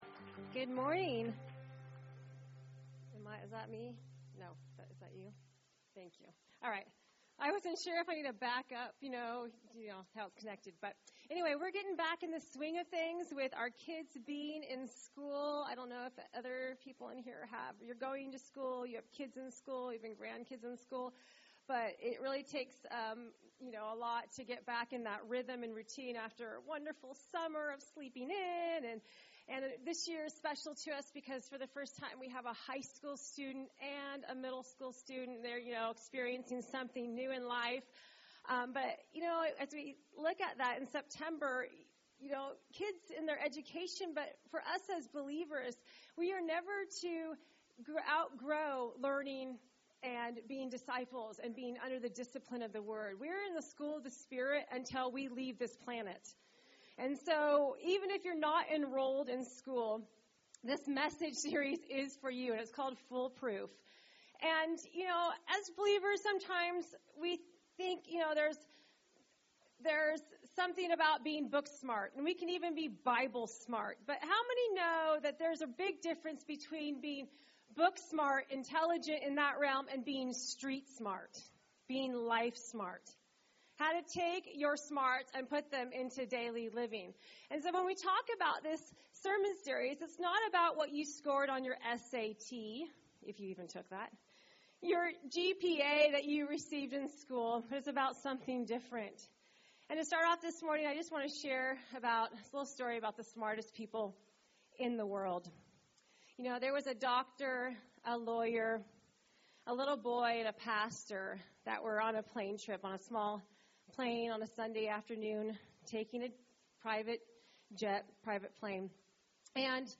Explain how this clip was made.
Recorded at New Life Christian Center, Sunday, September 6, 2015 at 9 AM.